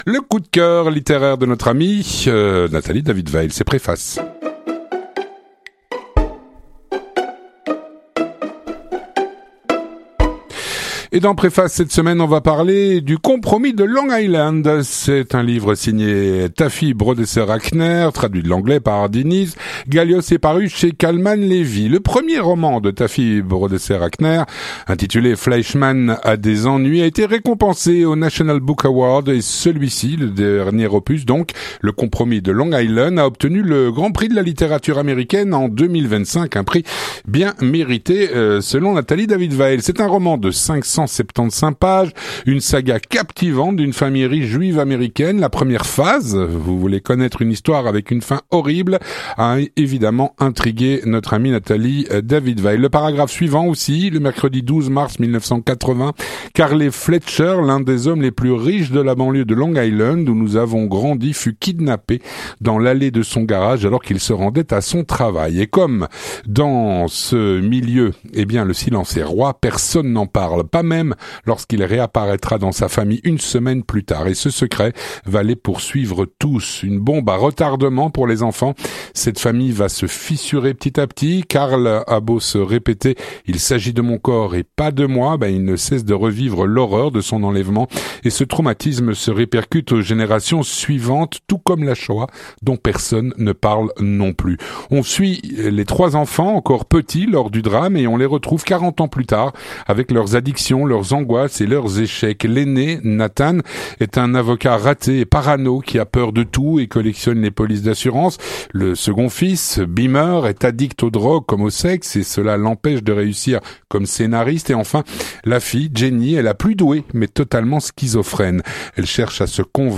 3. Préface